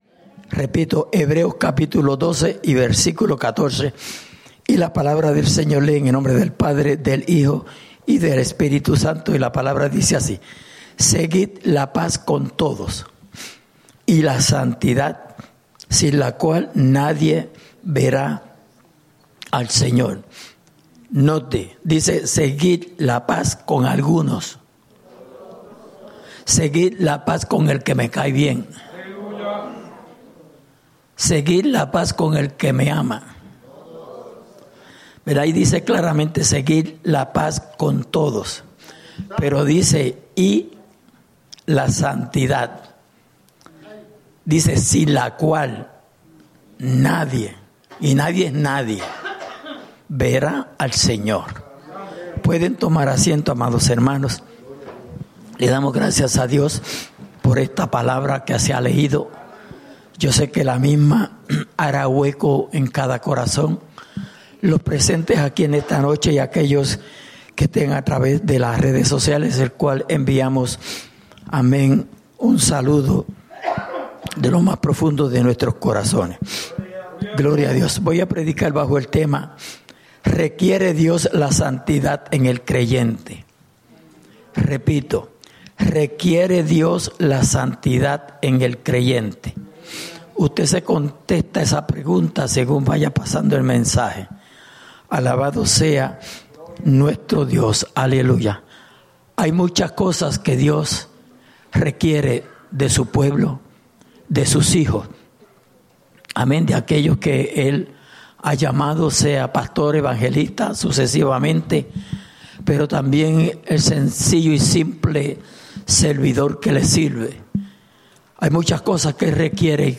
Souderton, PA